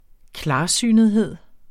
Udtale [ ˈklɑːˌsyˀnəðˌheðˀ ]